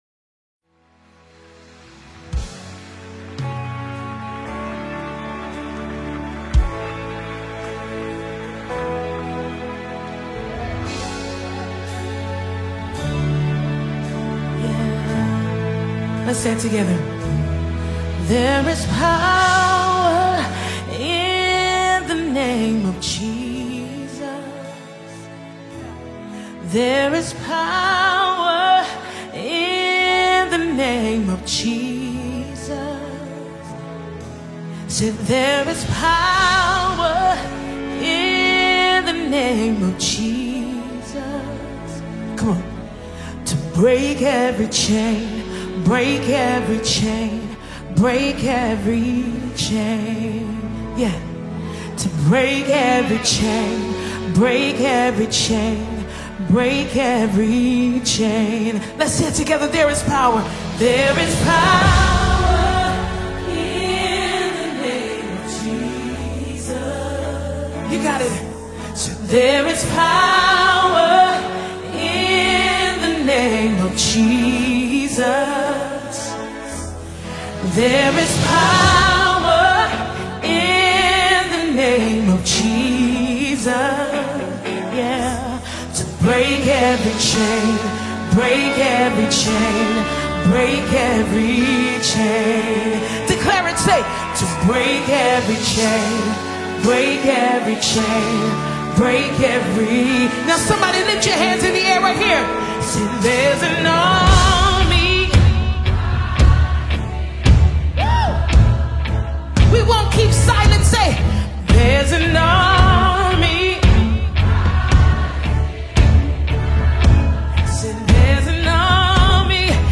worship anthem
Gospel Songs